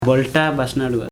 Dialect: Hill Remo